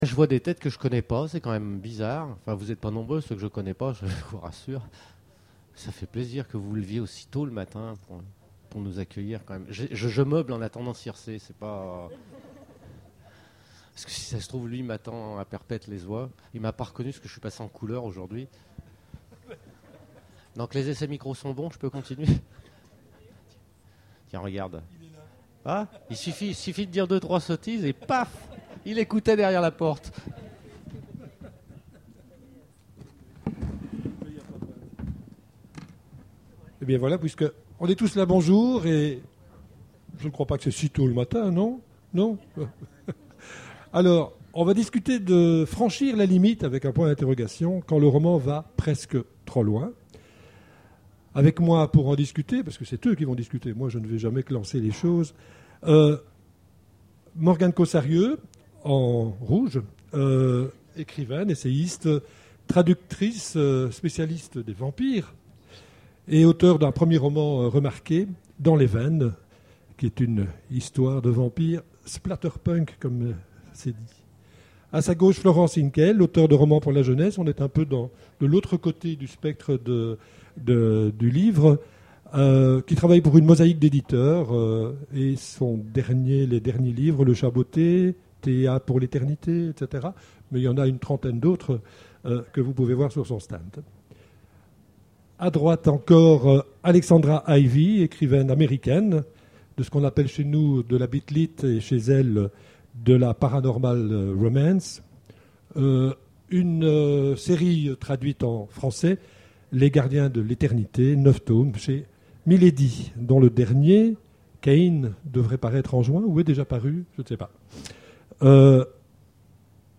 Imaginales 2013 : Conférence Franchir la limite ?